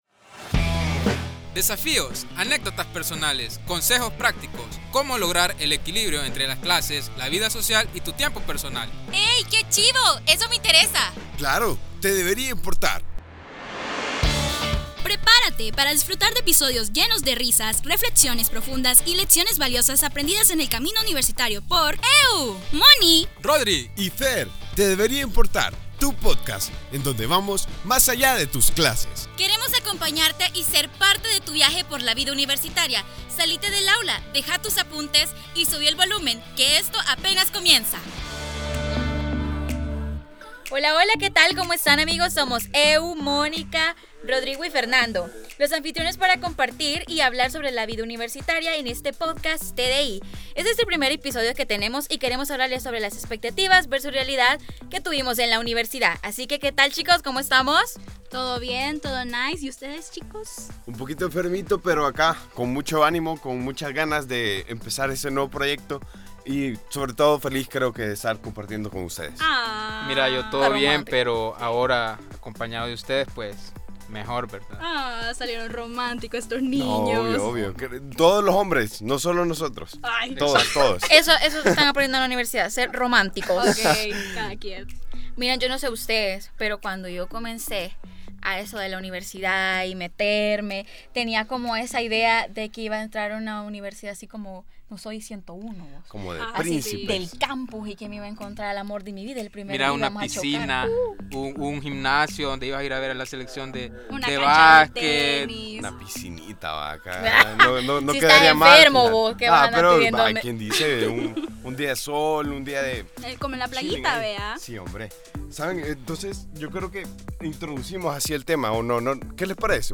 Cuatro estudiantes nos guían a través de una experiencia universitaria en su máxima expresión, donde exploran sus propias vivencias iniciales en la universidad, desde las expectativas tejidas alrededor del primer día hasta lo vivido dentro de las aulas.